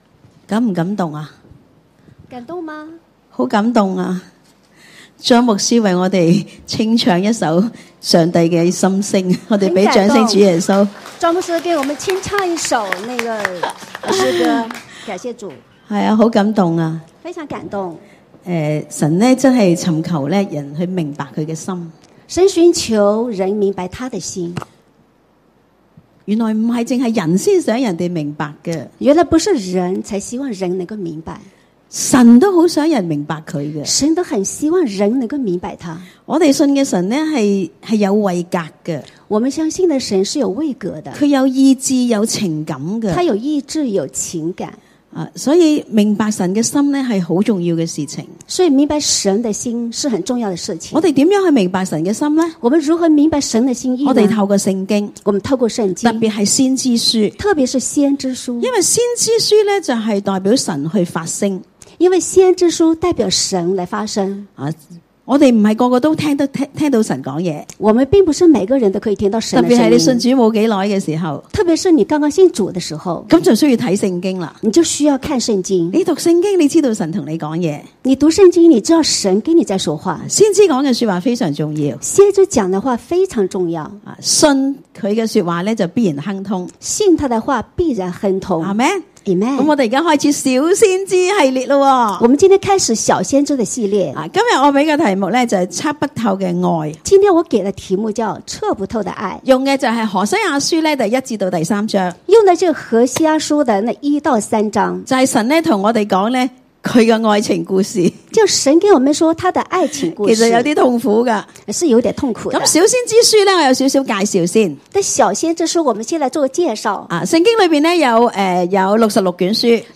下載 主日崇拜信息：小先知系列(一) 測不透的愛 （何一至三章）